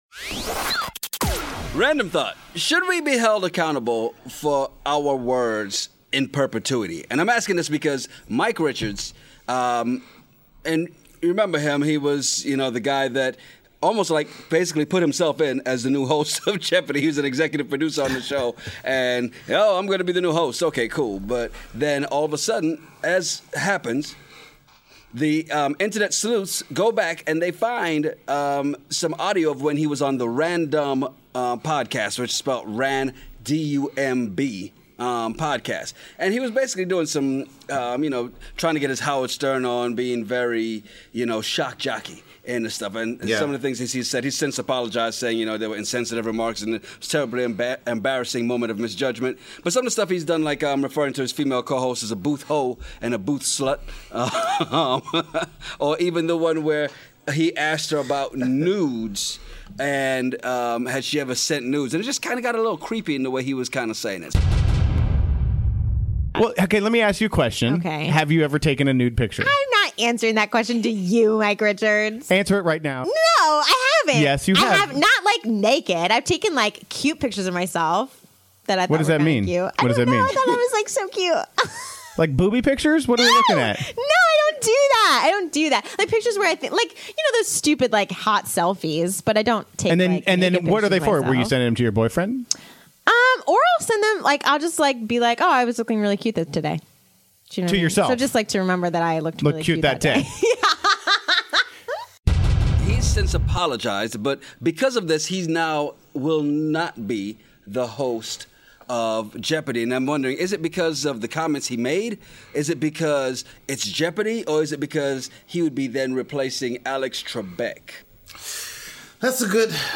Well we are those guys and we have been having these conversations since college.